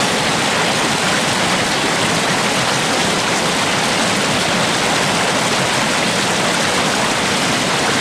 River.ogg